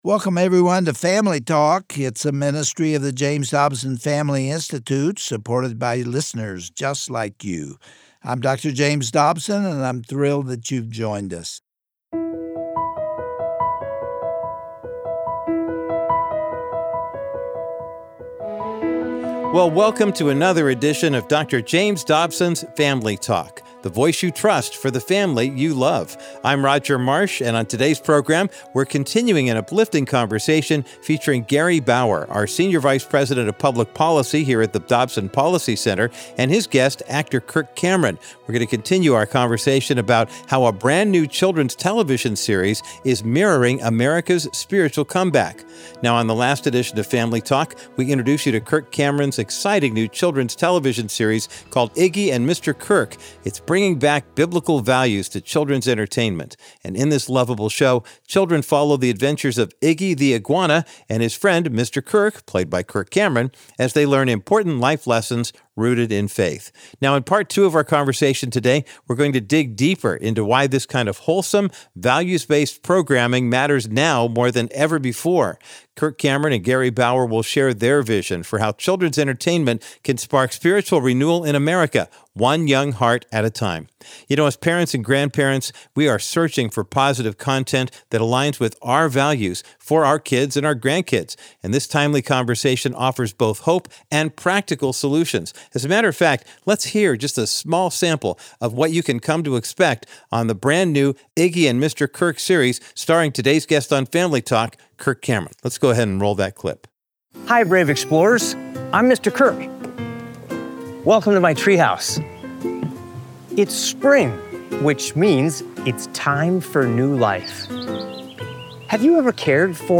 Gary Bauer continues his conversation with Kirk Cameron about the new faith-based children’s show called, Adventures with Iggy and Mr. Kirk.